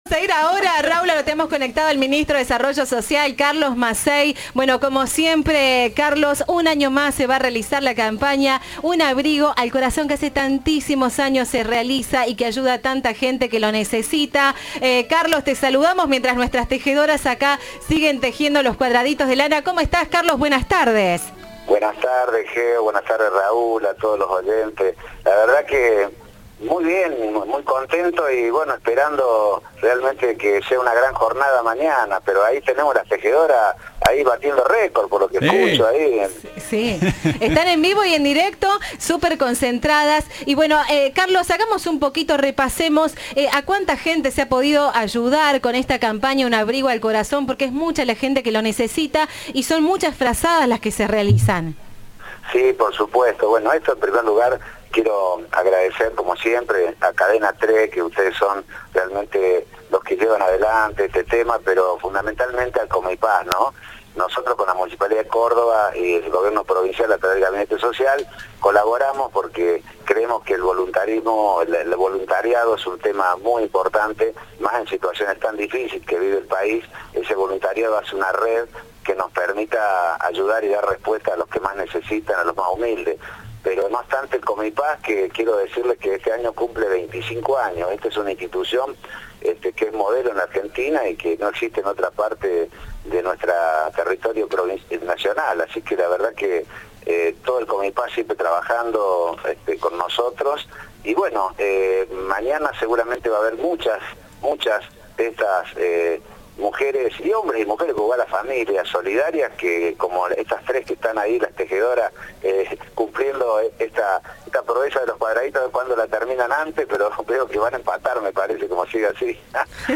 Antes de "Un abrigo al corazón", tres voluntarias tejieron en vivo en Cadena 3
En la previa de una nueva edición de "Un Abrigo al Corazón", tres tejedoras se animaron a una competencia en los estudios de Cadena 3.